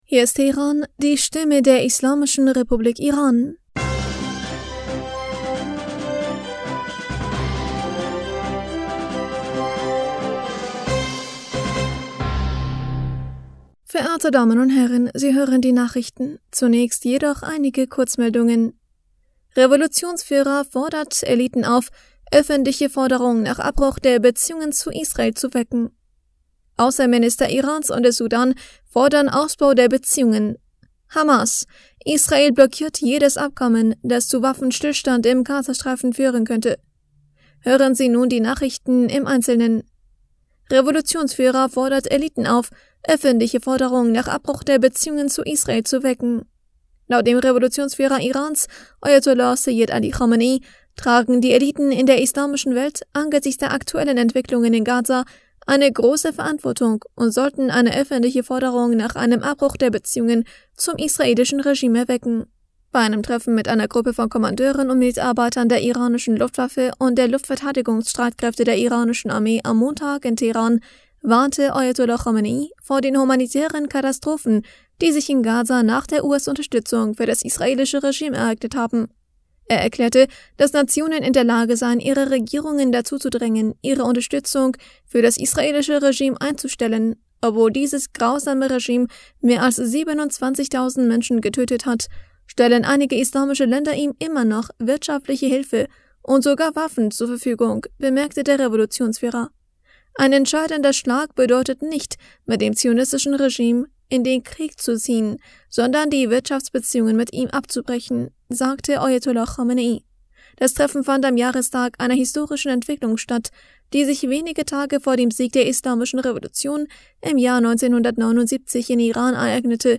Nachrichten vom 6. Februar 2024